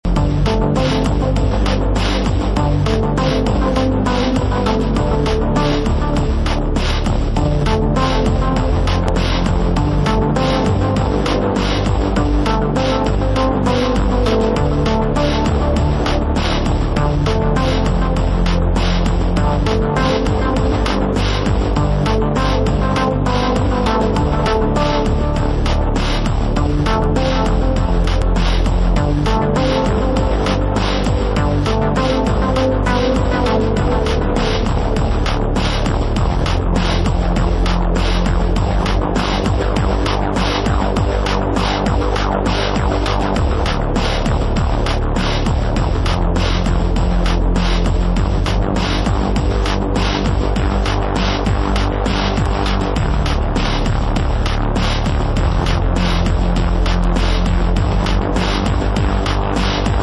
Beautiful album filled with moody breaks and electronics...
Electronix Techno